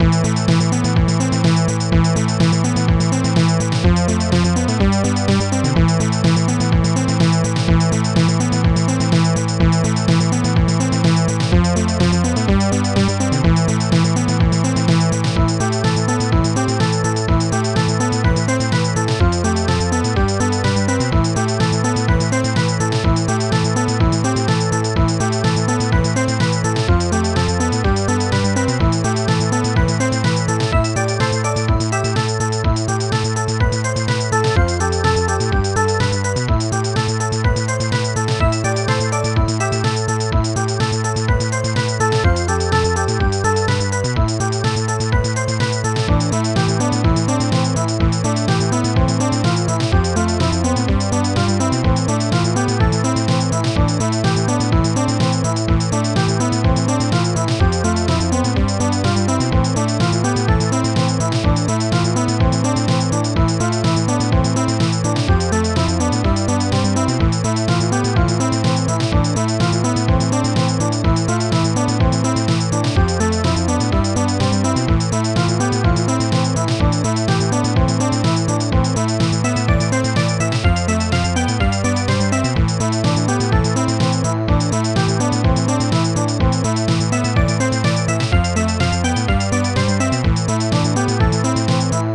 Protracker and family
st-01:popsnare2
st-02:bassdrum4
st-01:hihat1